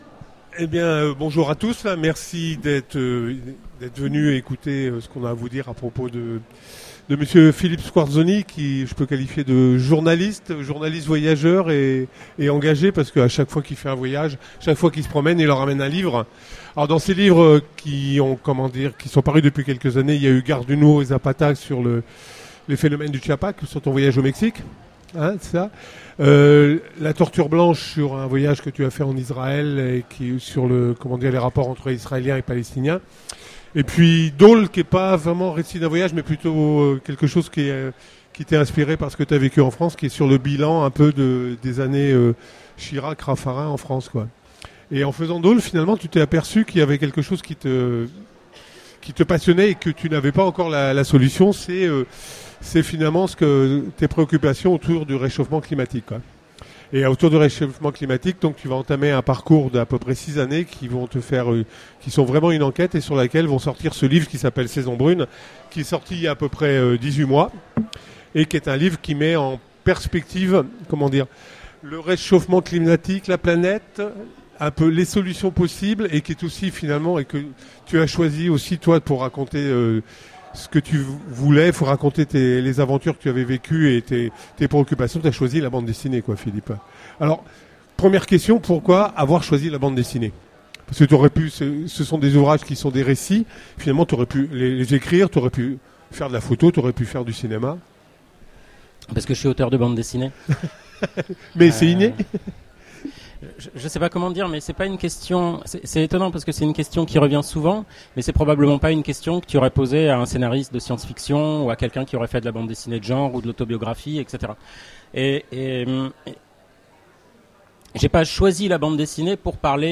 Utopiales 13 : Conférence
Mots-clés Rencontre avec un auteur Conférence Partager cet article